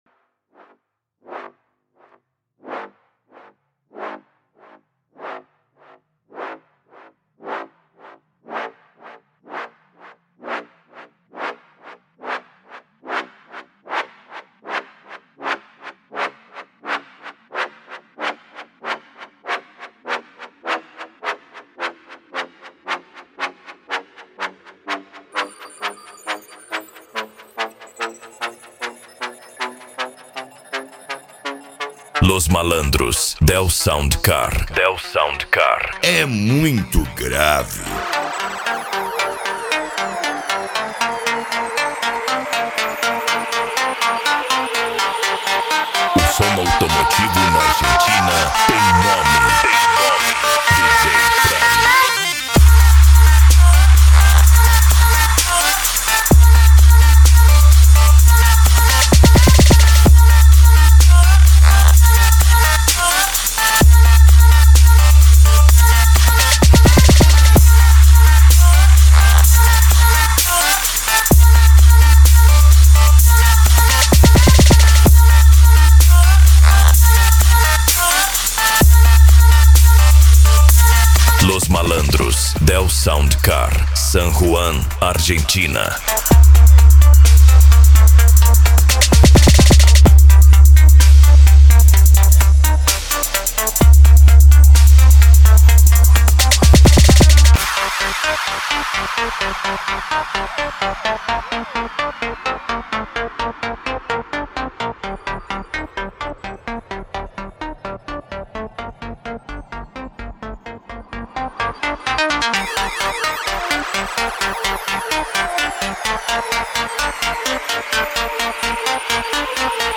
Bass
Eletronica
Psy Trance